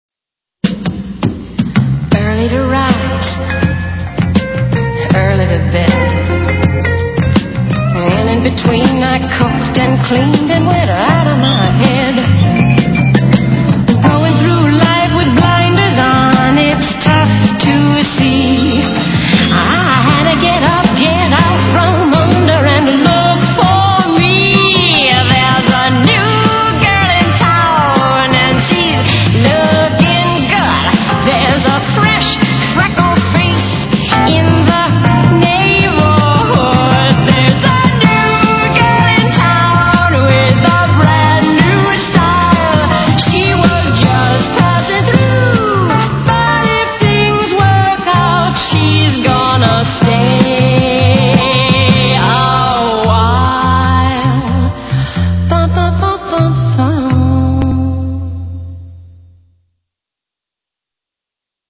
Opening Theme